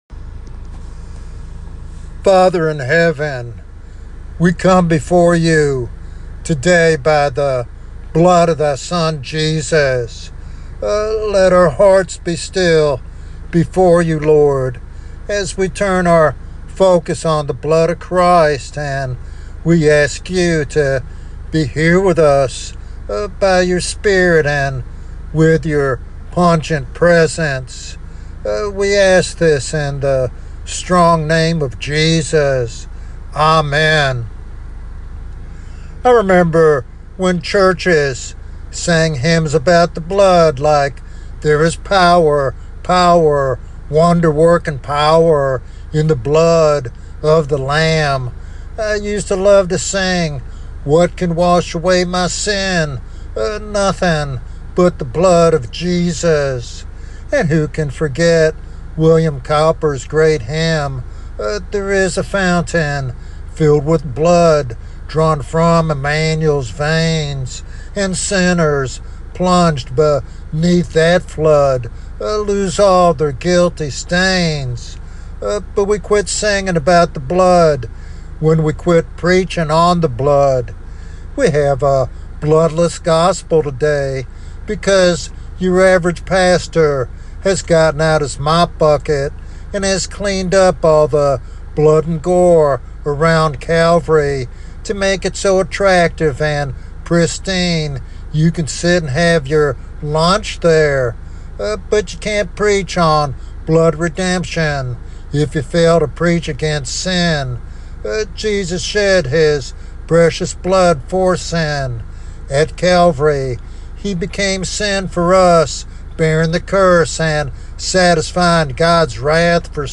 In this powerful sermon